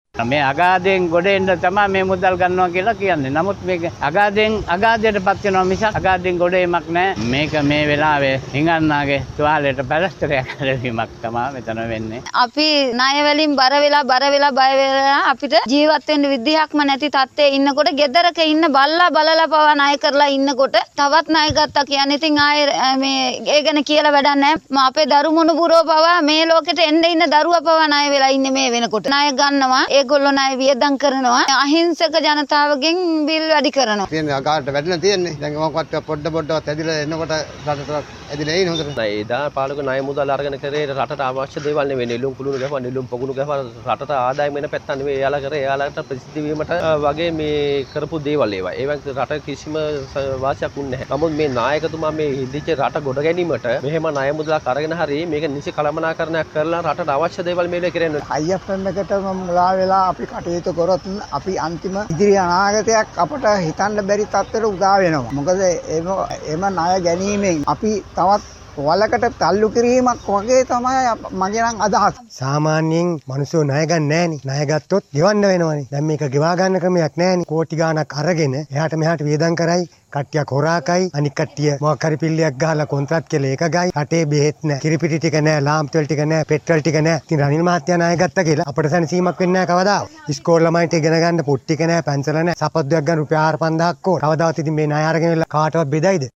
මේ අතර අයි. එම් . එෆ් මුල්‍ය අරමුදල මෙරටට හිමිවීම සම්බන්ධව ජනතාව දැක්වූ අදහස් දැන්.